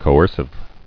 [co·er·cive]